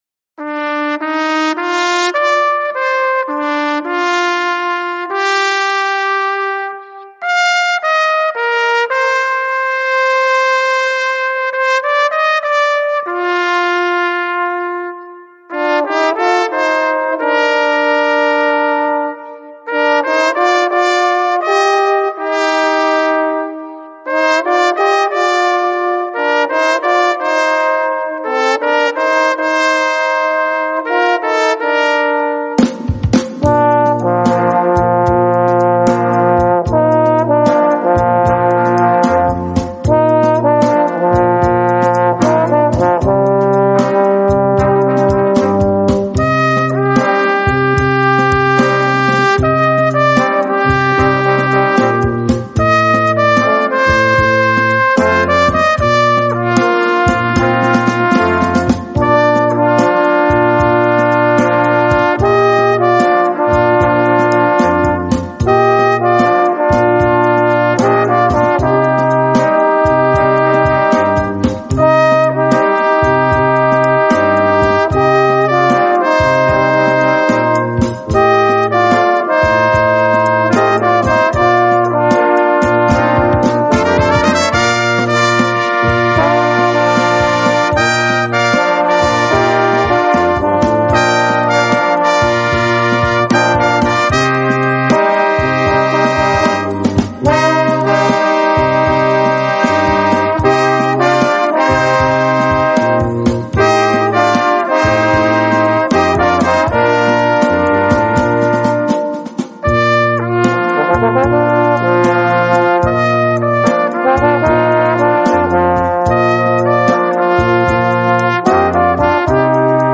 Gattung: Slow Rock für kleine Besetzung
Besetzung: Kleine Blasmusik-Besetzung
Schöner Slow Rock für Tenorhorn und Flügelhorn!